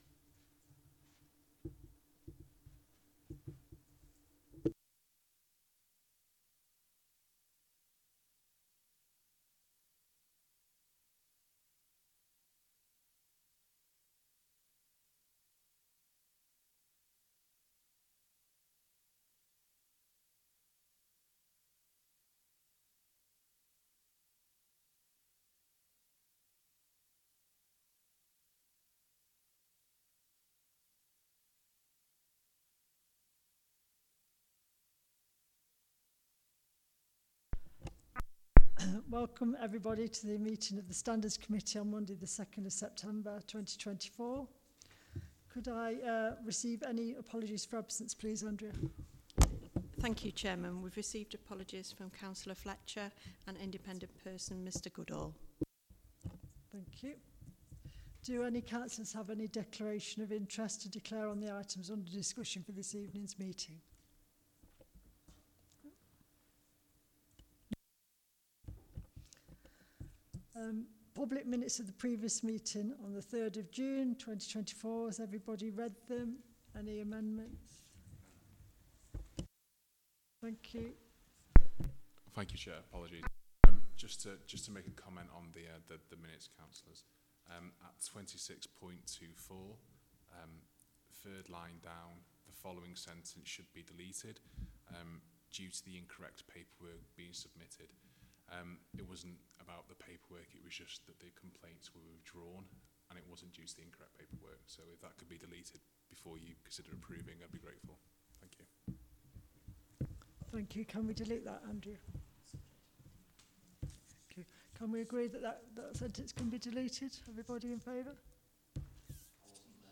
Coltman VC Room, Town Hall, Burton upon Trent
Meeting Recording